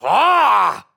Angry Birds 2 Leonard Angry Sound
Perfect For Unblocked Sound Buttons, Sound Effects, And Creating Viral Content.